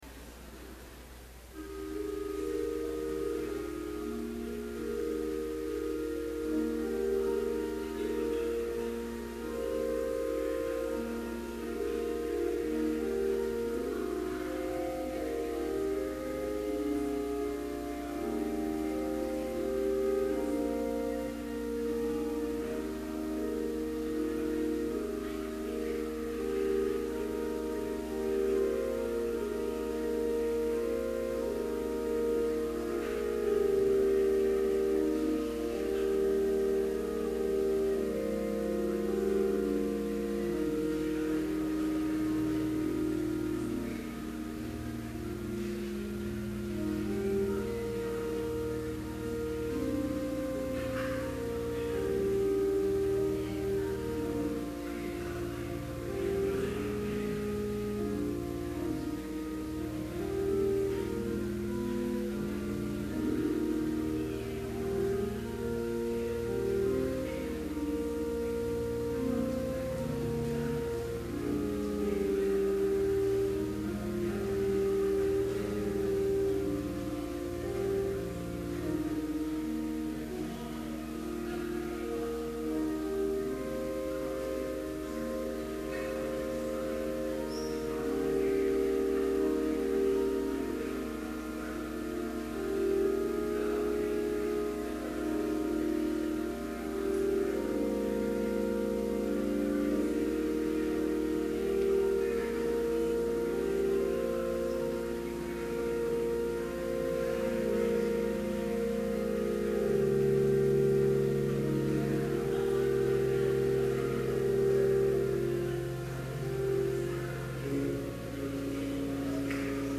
Complete service audio for Chapel - November 3, 2011
Prelude